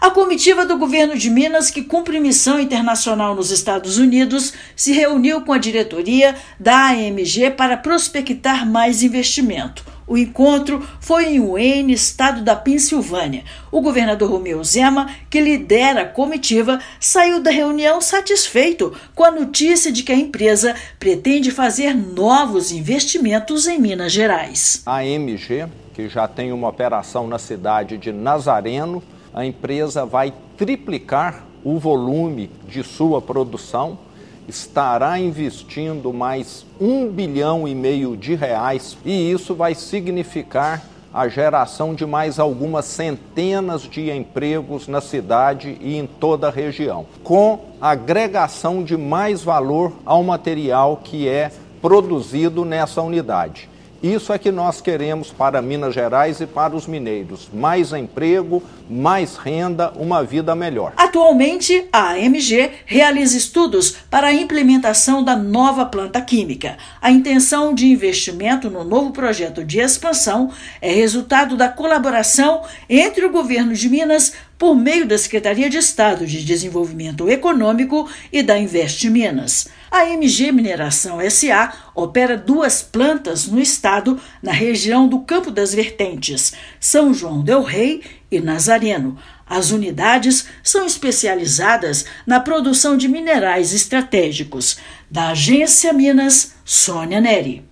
Novo projeto de empreendimento, anunciado nesta sexta-feira (17/5), prevê a criação de 277 novas oportunidades de trabalho diretas e indiretas para os mineiros. Ouça matéria de rádio.